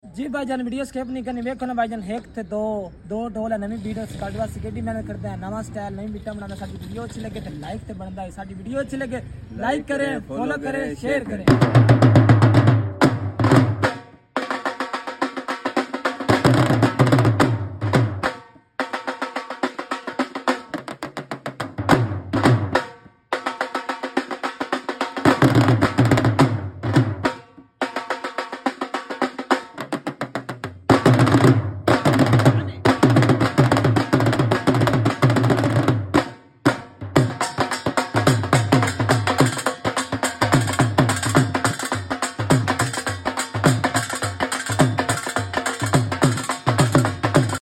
Dhol beat